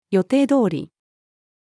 予定通り-female.mp3